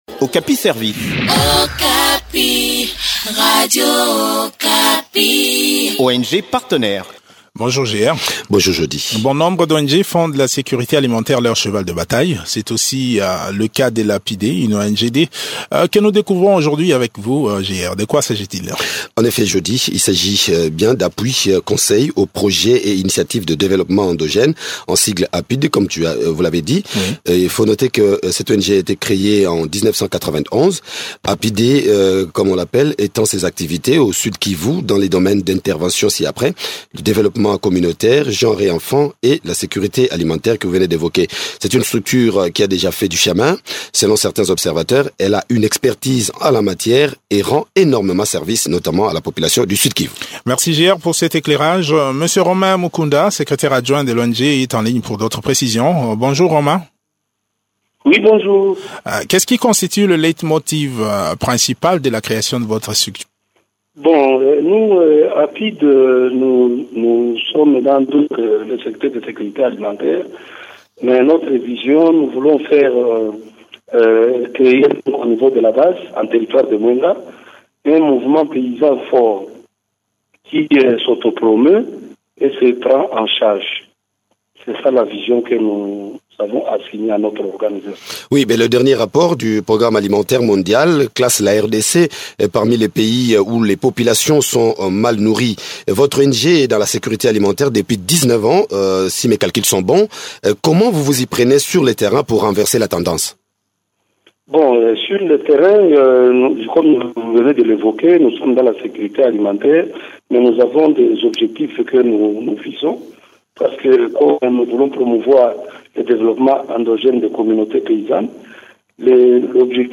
Depuis sa création en 1993, cette structure travaille essentiellement dans la lutte contre la malnutrition. Découvrez les activités de cette ASBL dans cet entretien